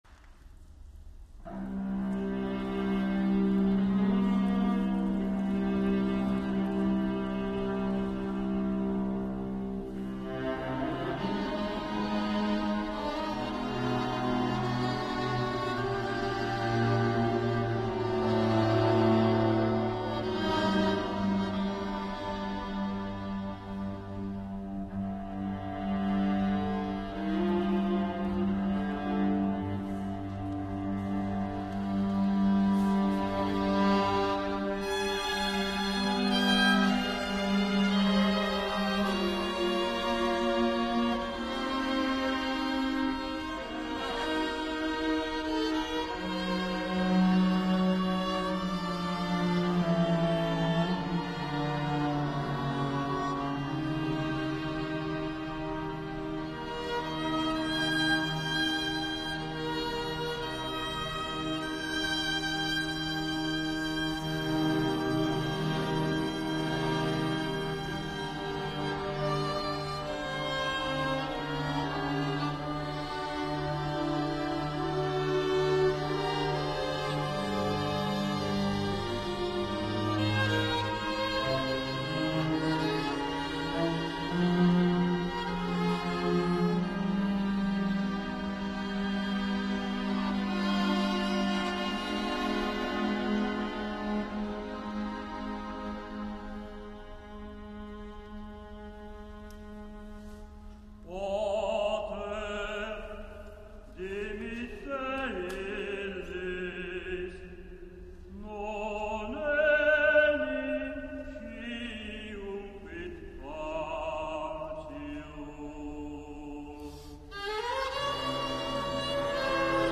Le sette parole di Cristo in croce (I-III-VI) Coro “S. Cecilia” di Calvisano e Gruppo d’Archi “Vergilius”